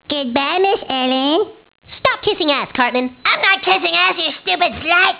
KISSING ASS Stan telling cartman to stop kissing ass.......